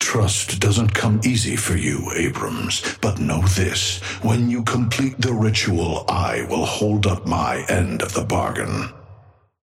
Patron_male_ally_atlas_start_04.mp3